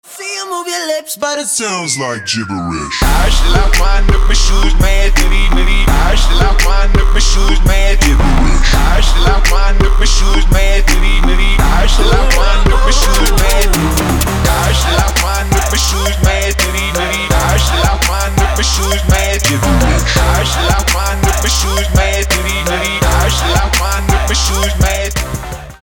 • Качество: 320, Stereo
Хип-хоп
Trap